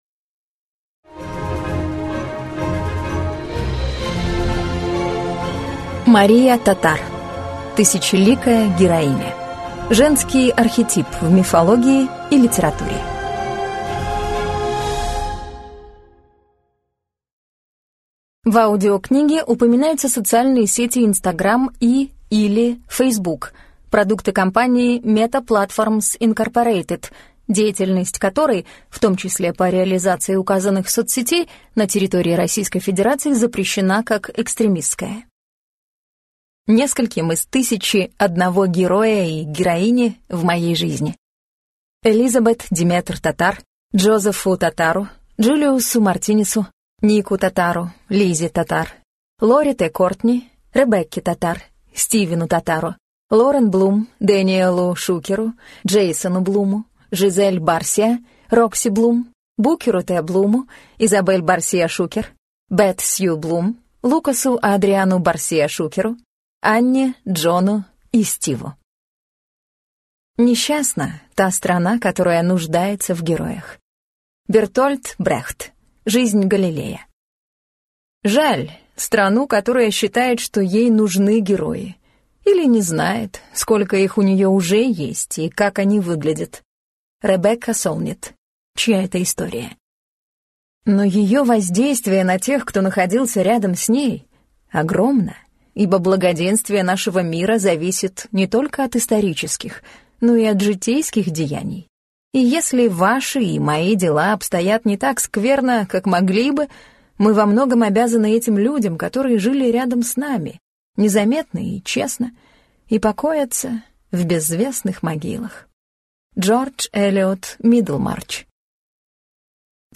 Аудиокнига Тысячеликая героиня: Женский архетип в мифологии и литературе | Библиотека аудиокниг